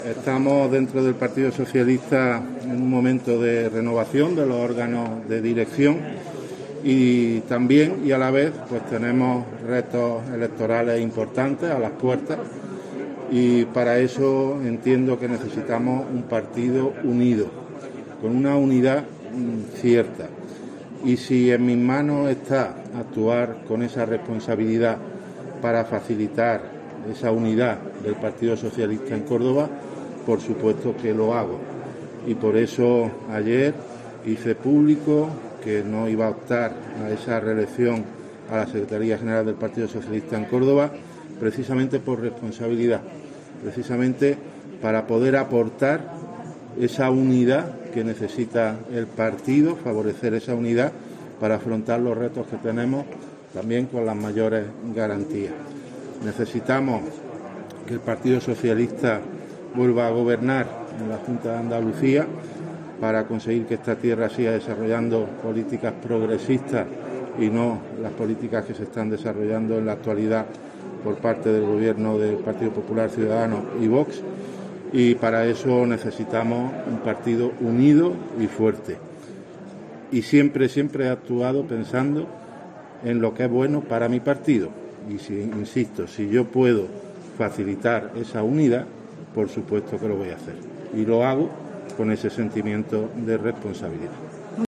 En este sentido y en declaraciones a los periodistas, Ruiz ha recordado que "estamos en el PSOE en un momento de renovación de los órganos de dirección, y a la vez tenemos retos electorales importantes a las puertas", y para afrontarlos entiende Ruiz que se precisa en Córdoba "un partido unido, con una unidad cierta, y si en mis manos está actuar, con responsabilidad, para facilitar esa unidad del PSOE en Córdoba, por supuesto que lo hago".